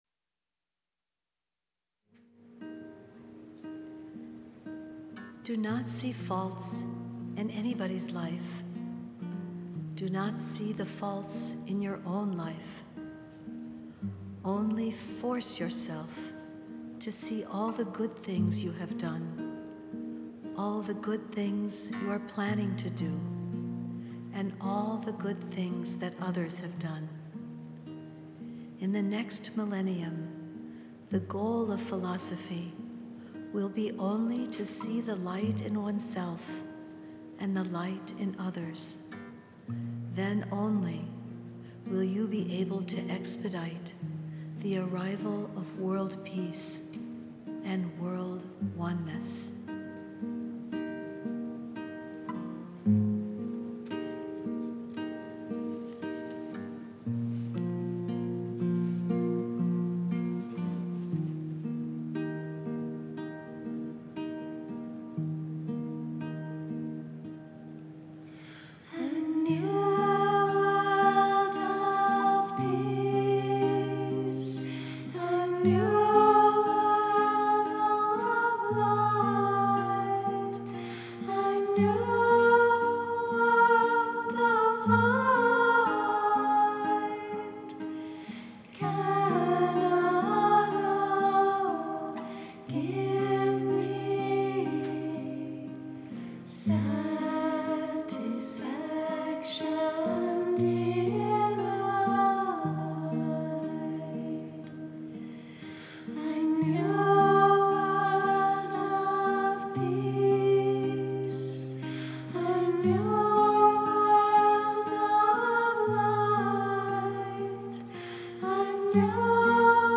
soulful performance